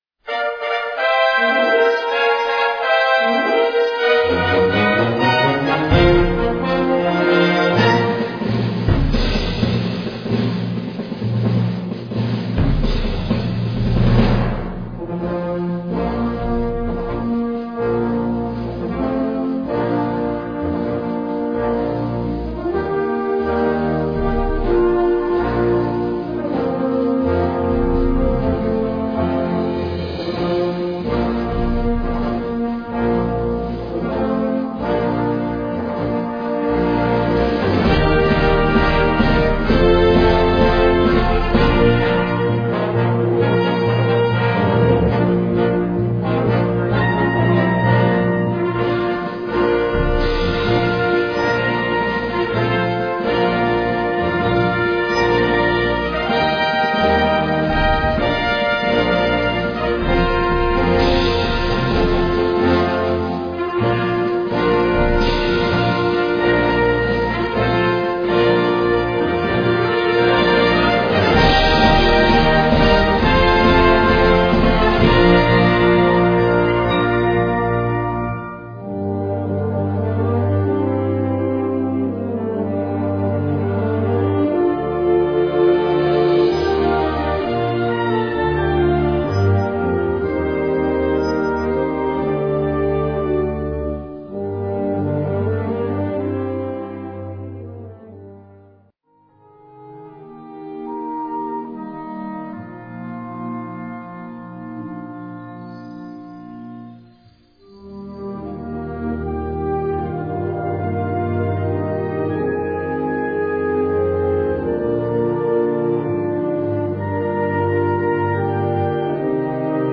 Kategorie Blasorchester/HaFaBra
Unterkategorie Eröffnung-, Abschluss- und Zugabenmusik
Besetzung Ha (Blasorchester)